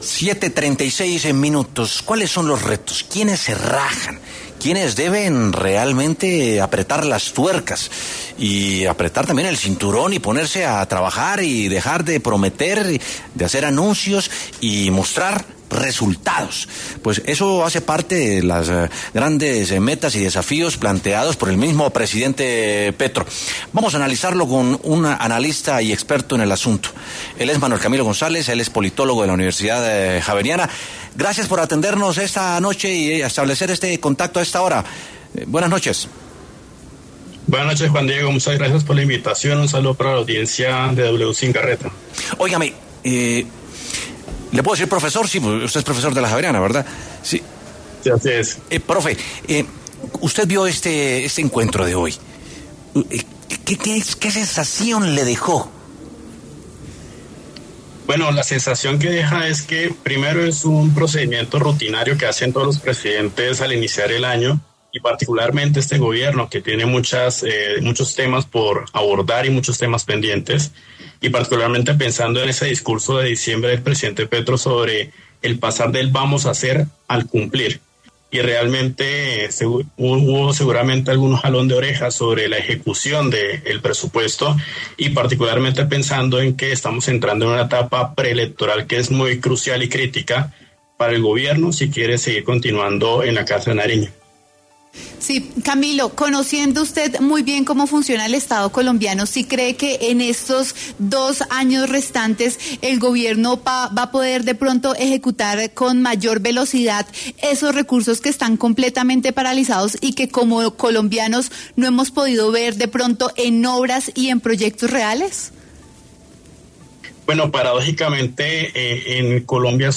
W Sin Carreta conversó con un politólogo para saber qué pudo haber pasado en el cónclave del presidente Gustavo Petro con sus ministros.